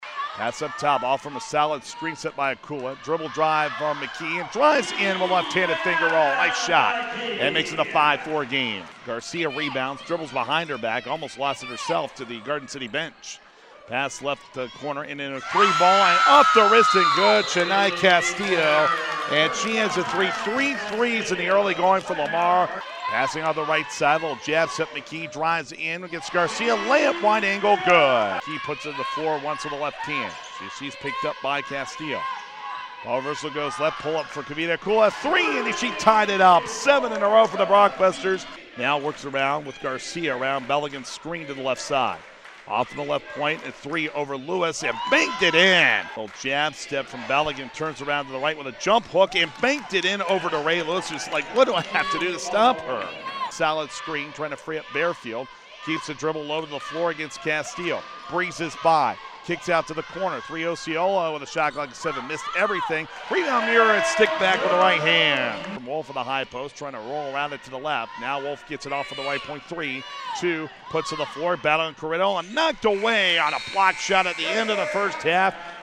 Women’s Highlights First Half
gccc-lamar-highlights-first-half.mp3